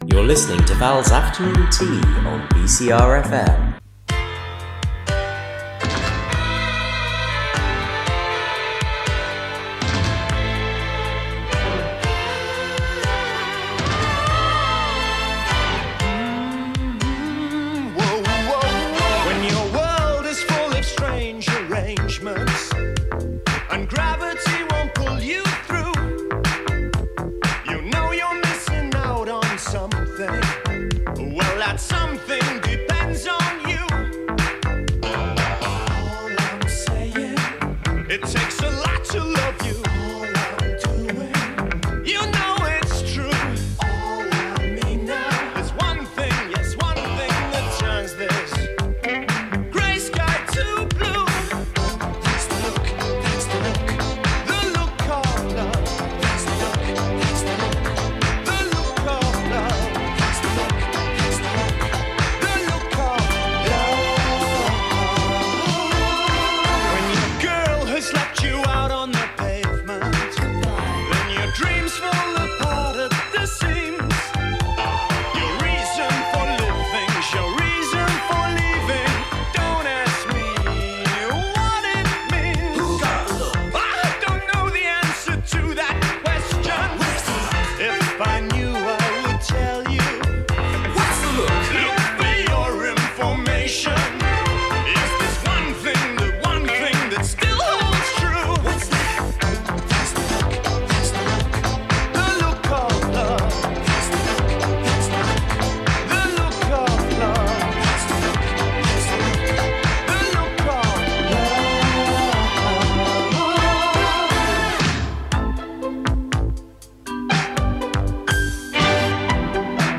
There's an hour of upbeat love songs and me chatting away.